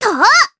Lilia-Vox_Attack3_jp.wav